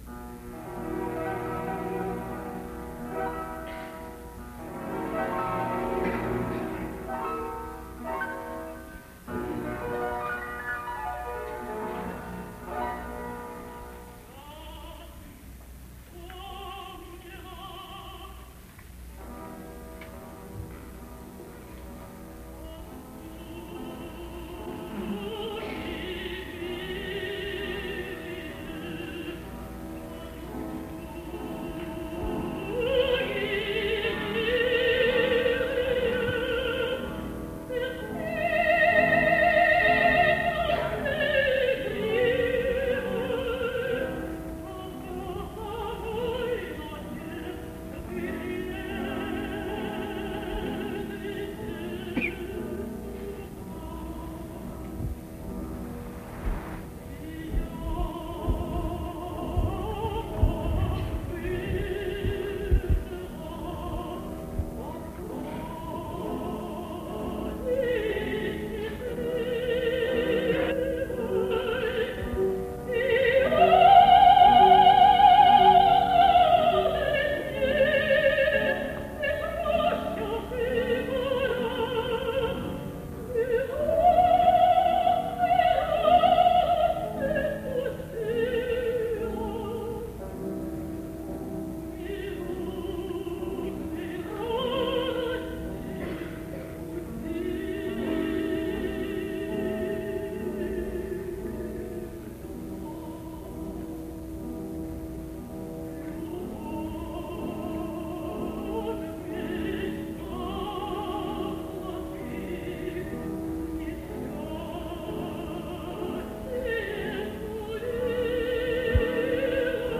Ири́на Константи́новна Архи́пова (2 января 1925, Москва, СССР — 11 февраля 2010, Москва, Россия) — советская и российская камерная и оперная певица меццо-сопрано, музыкальный педагог, профессор Московской консерватории имени Петра Ильича Чайковского, общественный деятель; народный артист РСФСР (1961), народный артист СССР (1966).